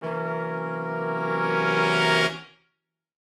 Index of /musicradar/gangster-sting-samples/Chord Hits/Horn Swells
GS_HornSwell-C7b2b5.wav